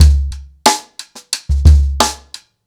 • 121 Bpm Breakbeat Sample C# Key.wav
Free breakbeat - kick tuned to the C# note. Loudest frequency: 1389Hz
121-bpm-breakbeat-sample-c-sharp-key-esn.wav